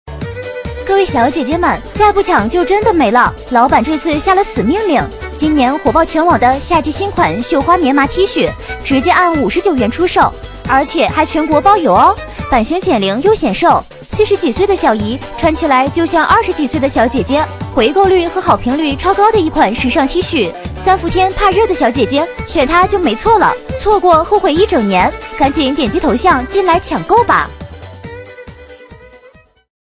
B类女13
【女13号抖音甩卖配音】再不抢就真的没了